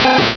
Cri de Rattata dans Pokémon Rubis et Saphir.